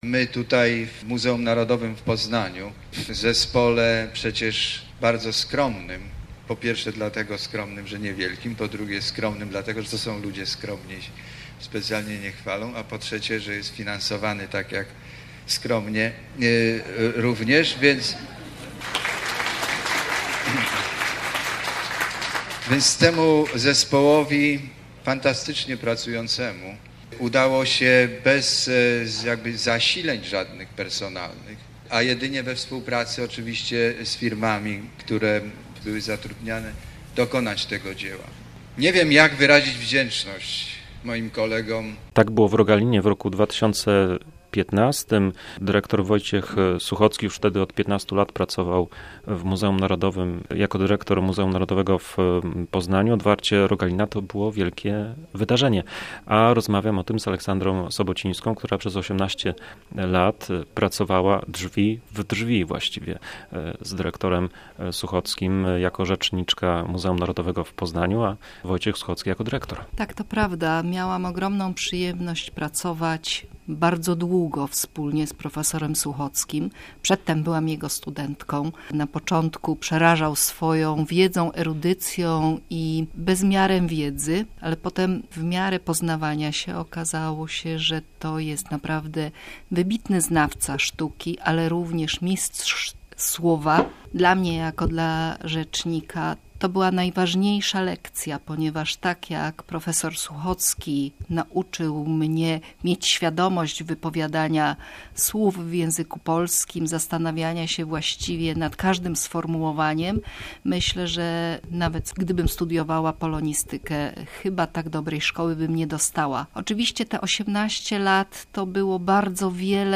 Ale najpierw przemówi sam profesor...